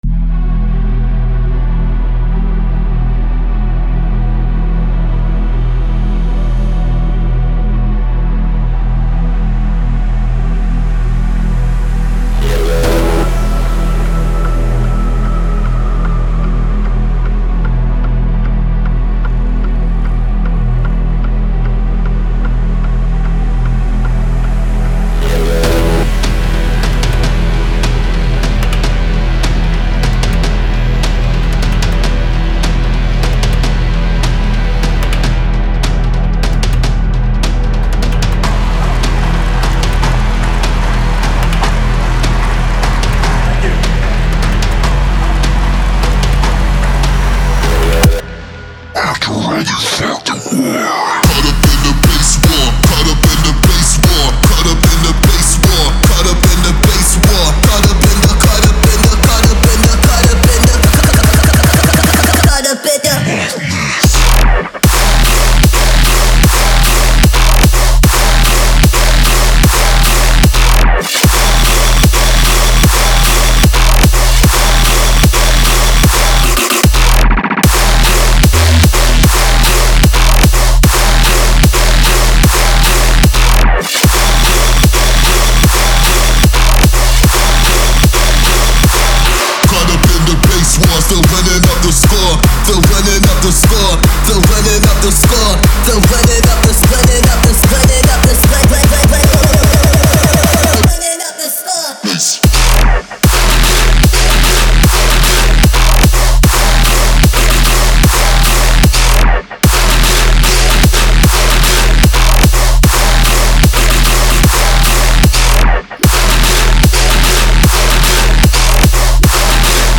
In the electrifying realm of tearout dubstep music